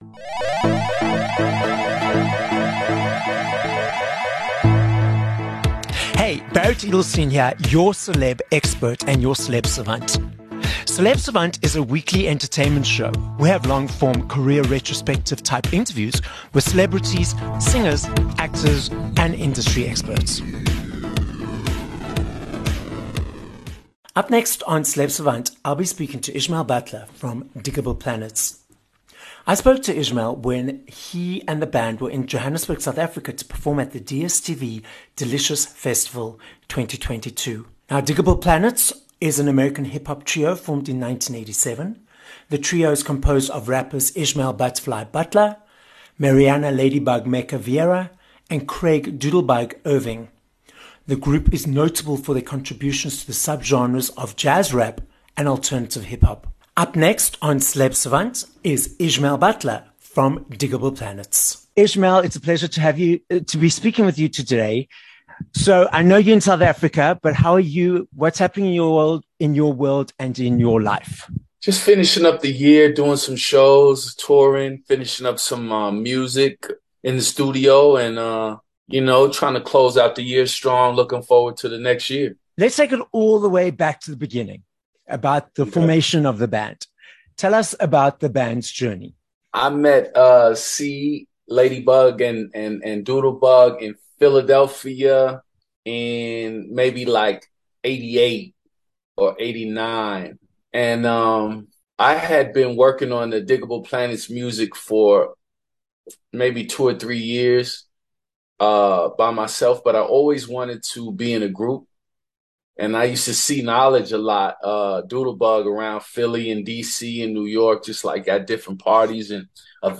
8 Dec Interview with Ishmael "Butterfly" Butler (from Digable Planets)